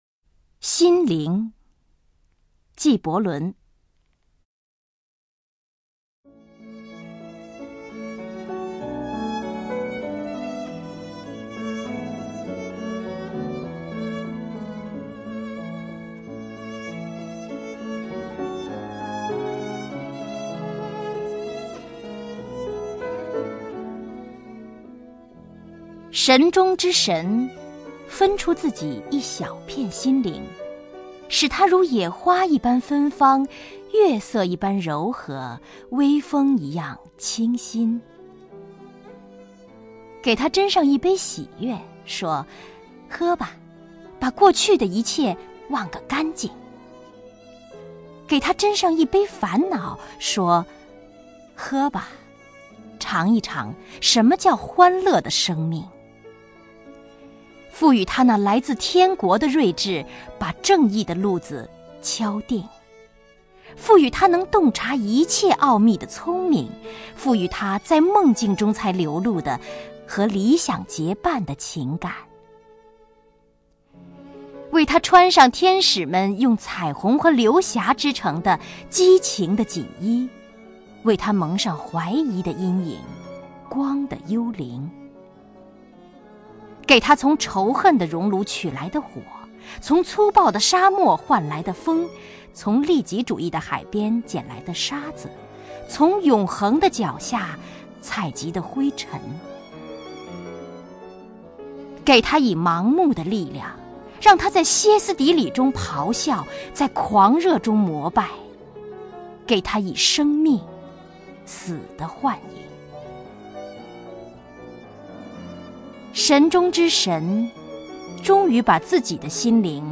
王雪纯朗诵：《心灵》(（黎）卡里·纪伯伦)
名家朗诵欣赏 王雪纯 目录